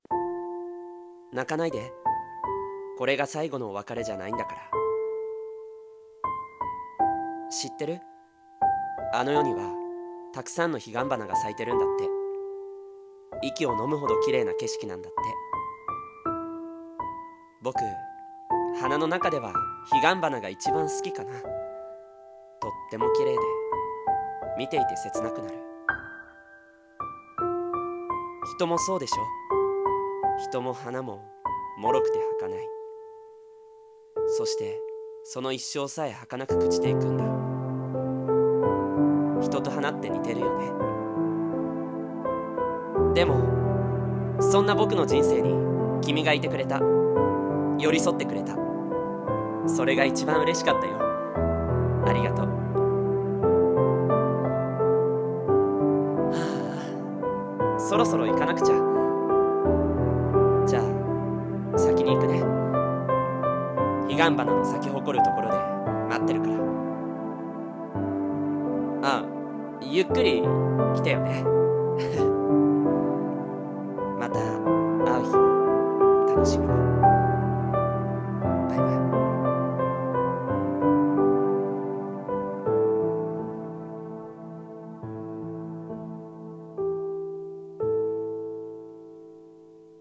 彼岸花【一人朗読台本】